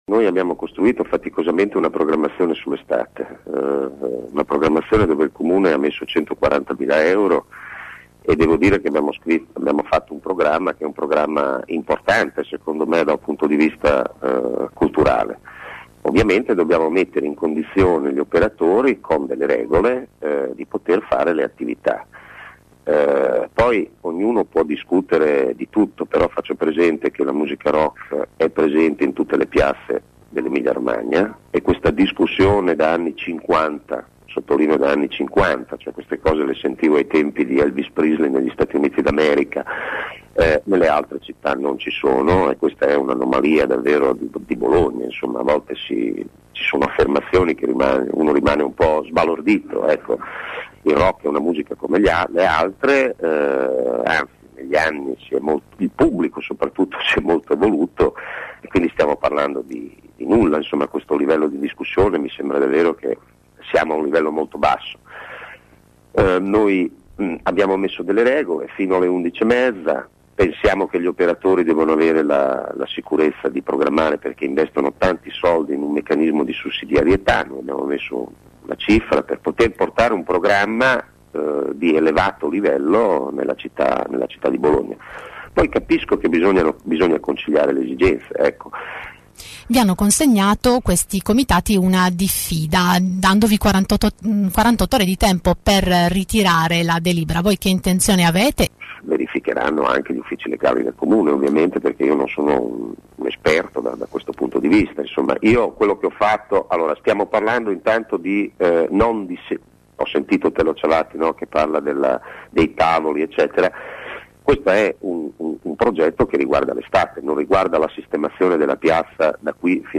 Oggi l’assessore alla cultura Alberto Ronchi ha risposto ai nostri microfoni: “Il rock è una musica come le altre, il pubblico si è evoluto”.